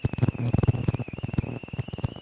描述：来自一体式计算机的噪音启用了wifi和蓝牙
标签： 收音机 静态 毛刺 EMI 电气 噪声
声道立体声